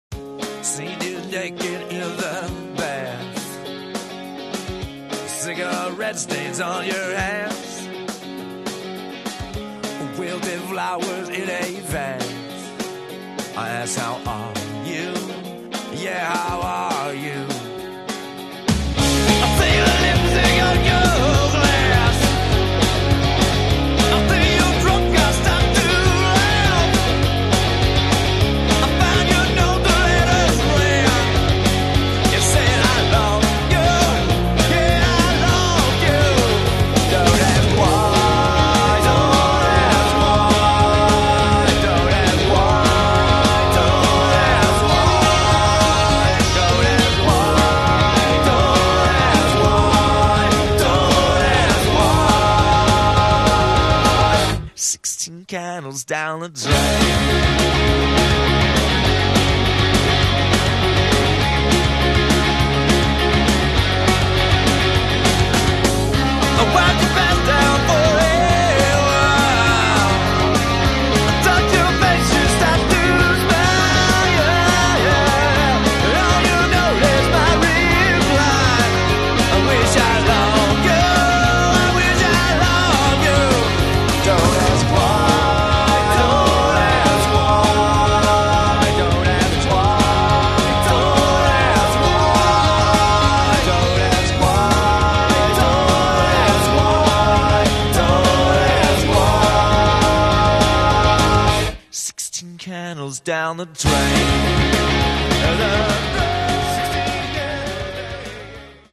Genre: Punk/Grunge